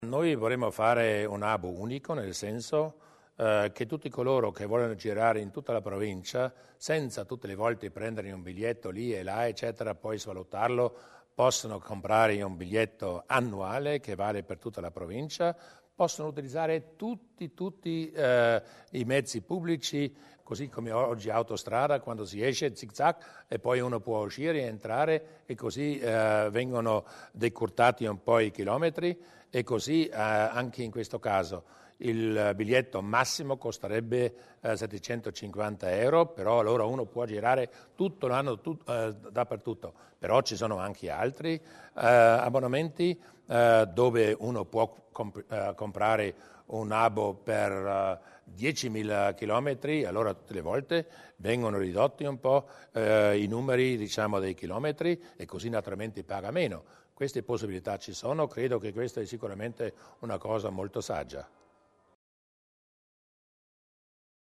Il presidente Durnwalder sulle novità del trasporto pubblico